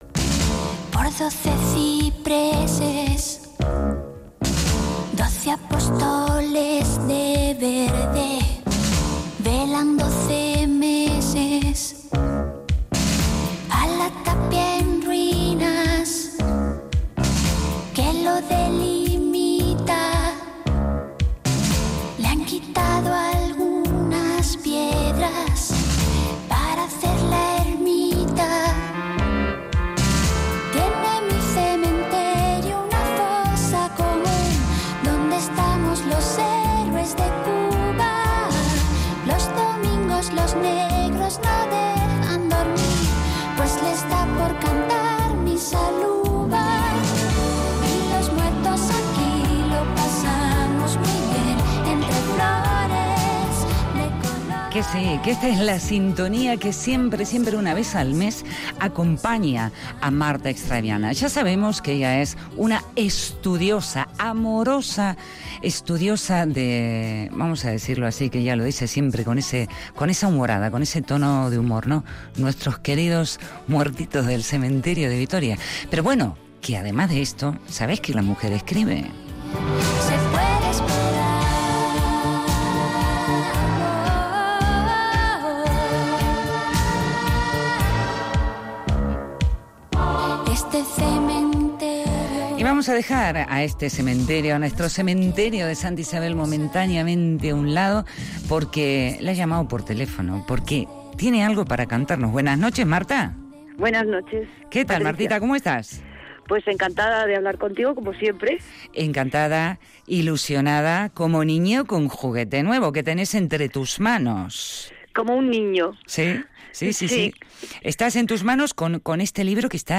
Radio Vitoria LA FIACA Entrevista